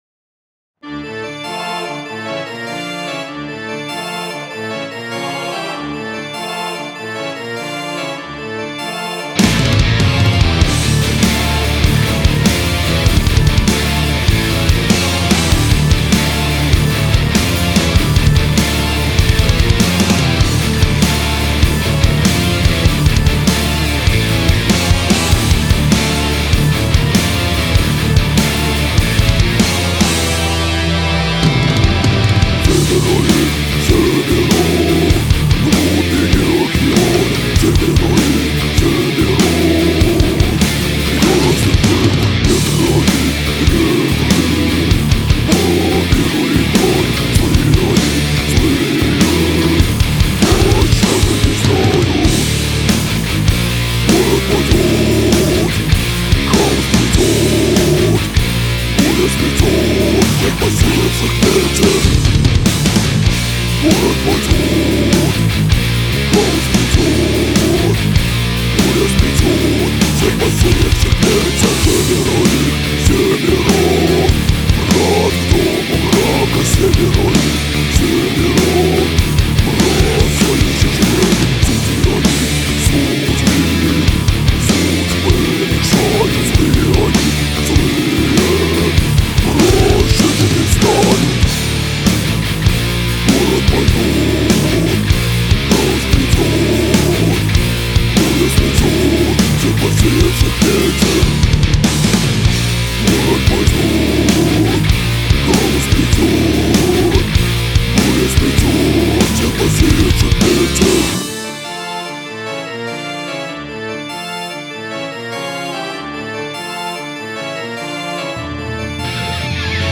А вот и наша первая серьёзная студийная работа!
Doom Death